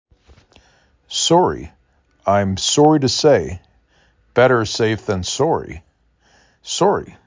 5 Letters, 2 Syllable
s ar E